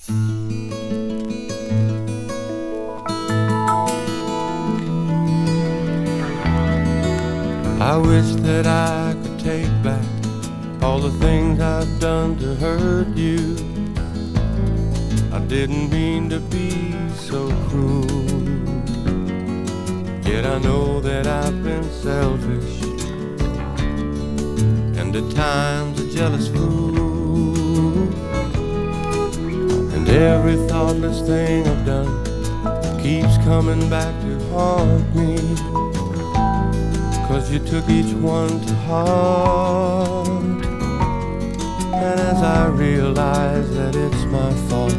以降、変わること無く、真摯な印象で、甘く伸びやかなヴォーカルが魅力です。
時代を感じさせるポップスやロックに、洗練されたストリングスアレンジも冴える、香り立つような良盤です。
Rock, Pop, Jazz, Folk　USA　12inchレコード　33rpm　Stereo